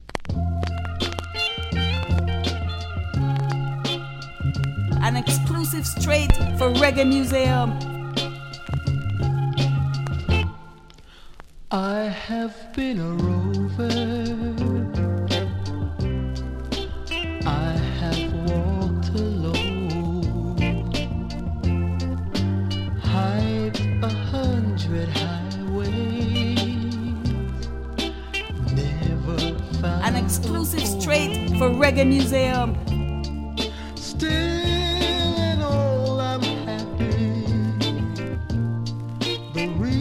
[A] Condition : G+ audible marks